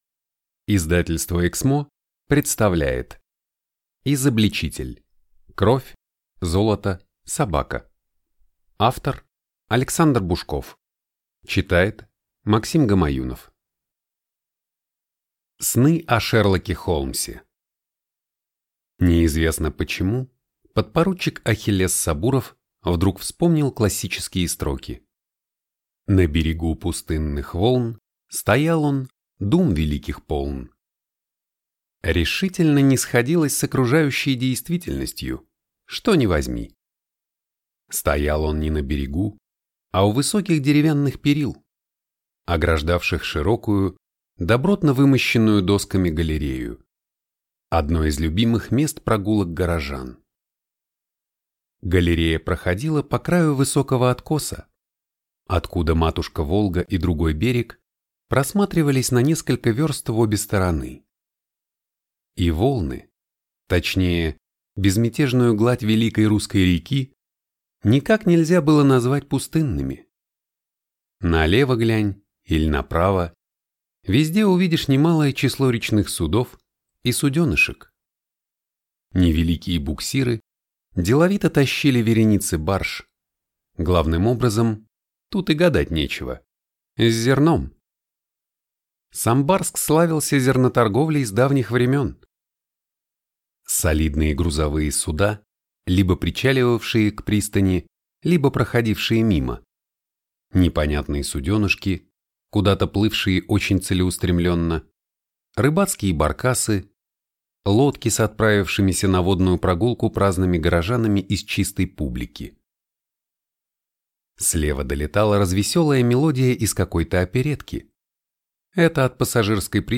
Аудиокнига Изобличитель. Кровь, золото, собака | Библиотека аудиокниг